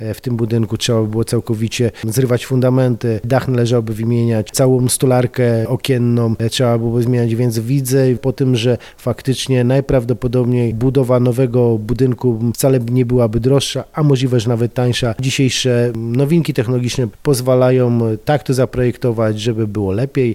Jakie będą dalsze losy tego miejsca, czy miastu bardziej opłaca się remont, czy postawienie nowego budynku, mówi Jerzy Zawodnik, Wiceprezydent Radomia